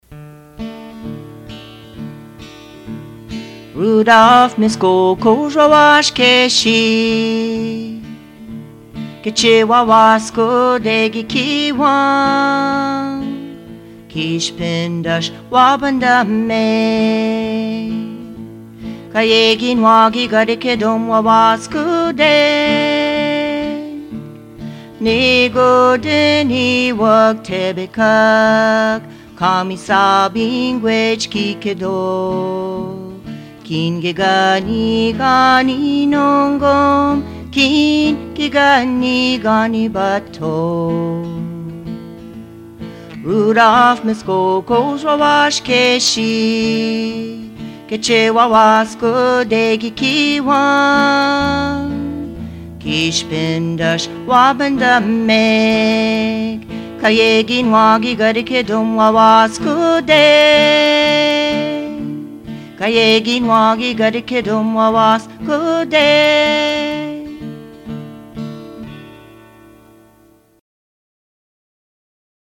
Notakotcikan (Musique)